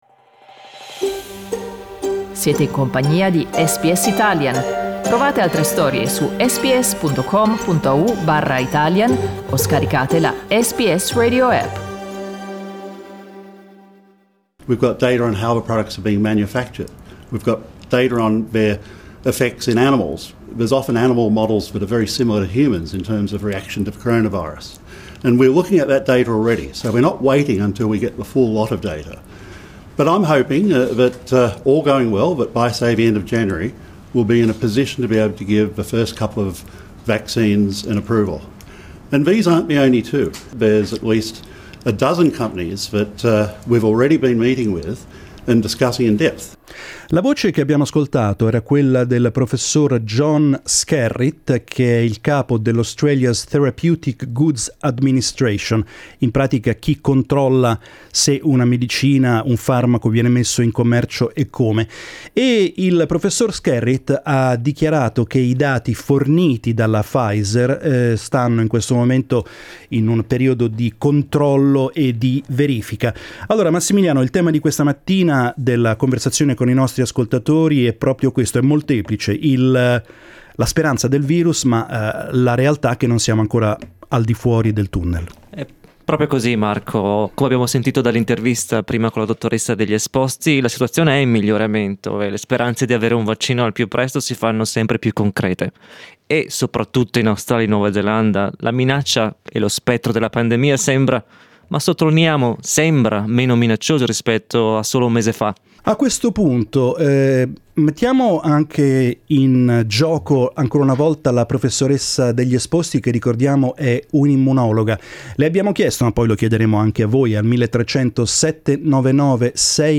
Vi preoccupa che al di fuori del Victoria così poche persone utilizzino la mascherina, oppure ormai pensate che la sua necessità possa essere oggetto di dibattito? Questa la domanda che abbiamo rivolto oggi in diretta ai nostri ascoltatori.